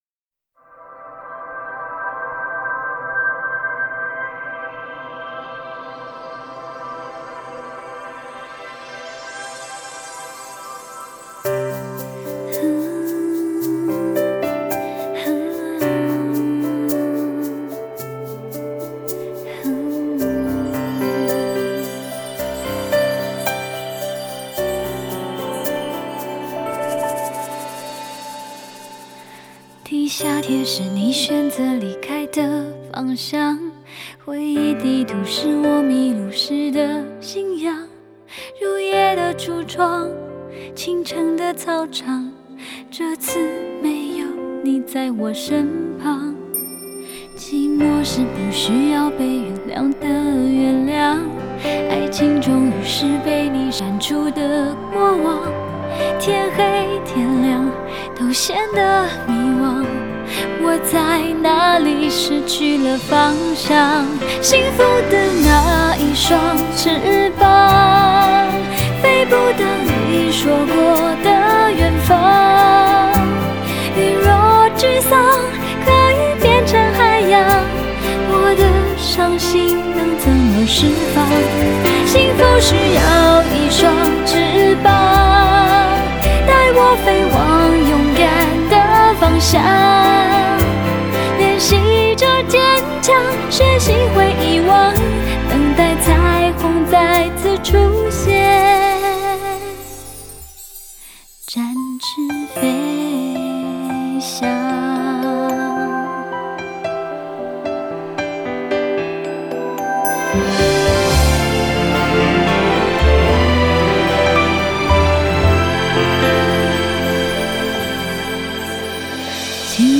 Ps：在线试听为压缩音质节选，体验无损音质请下载完整版 作词